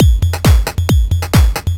DS 135-BPM C1.wav